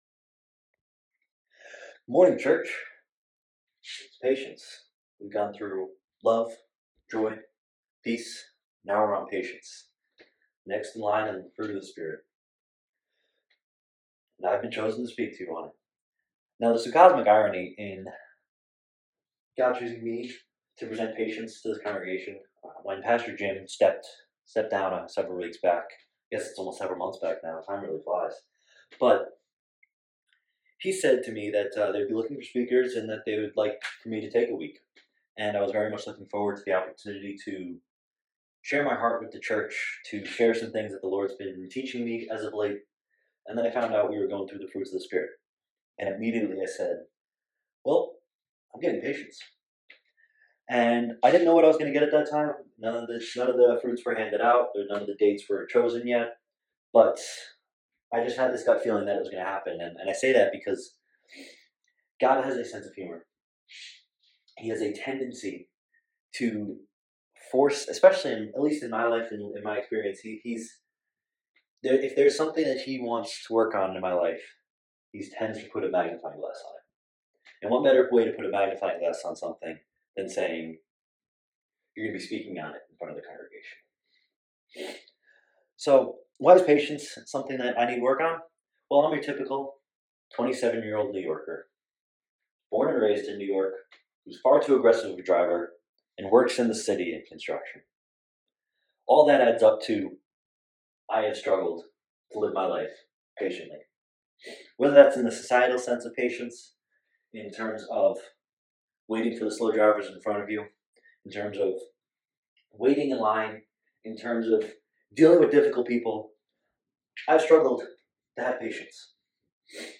Patience-Sermon.mp3